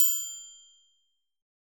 SouthSide Trap Bell (1).wav